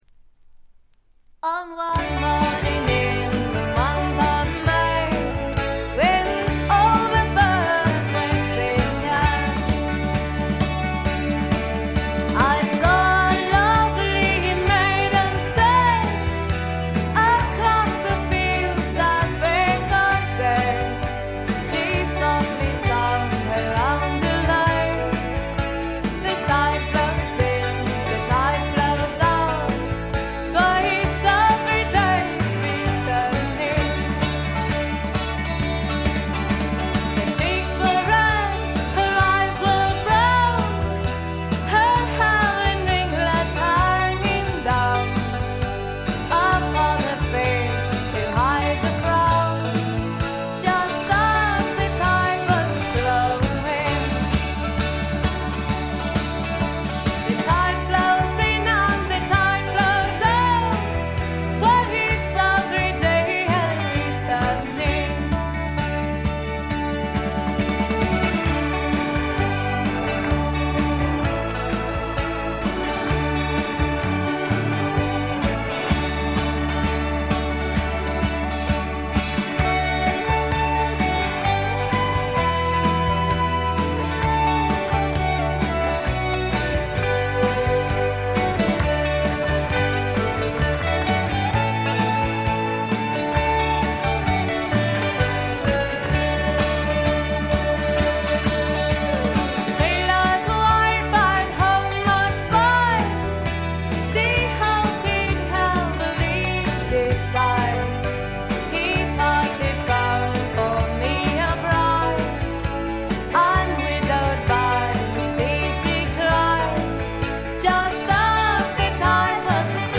部分試聴ですがわずかなノイズ感のみ。
80年代流フォーク・ロックの傑作！！
試聴曲は現品からの取り込み音源です。